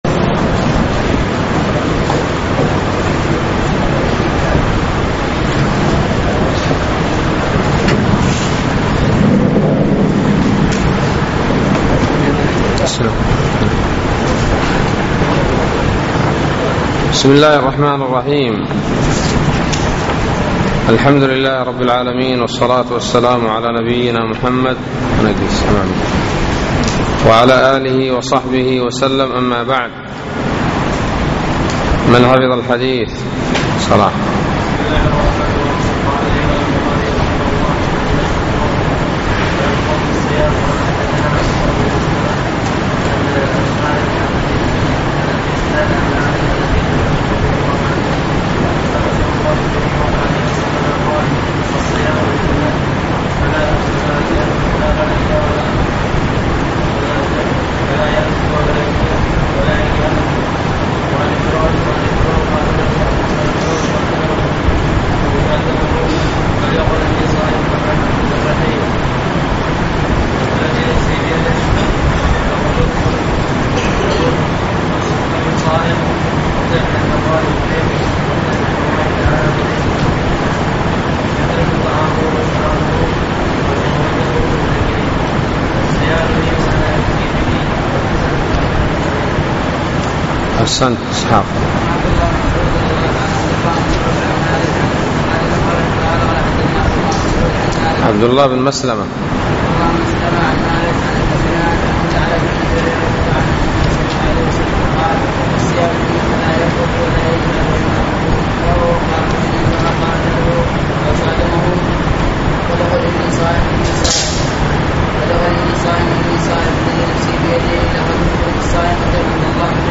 بسم الله الرحمن الرحيم الدرس الثالث : باب الصوم كفارة